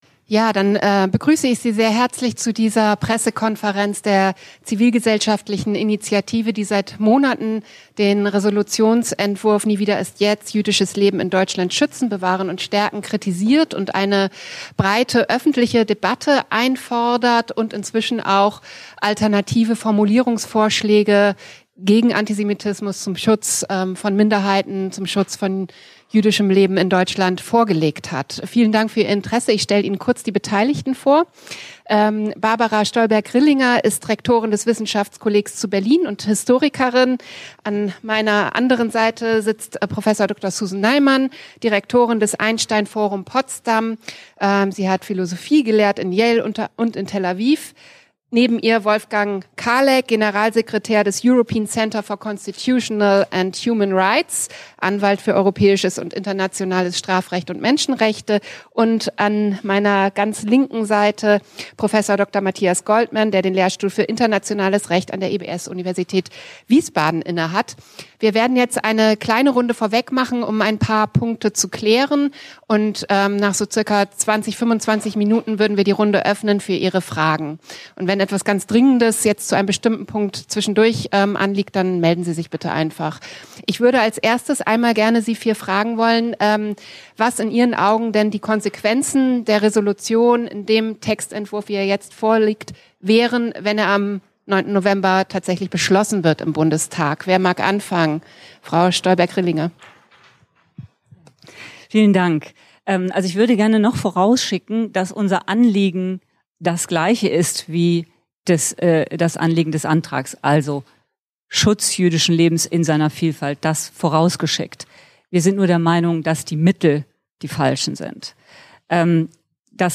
1 Massive Kritik an Antisemitismus-Resolution des Bundestages | Komplette PK 6.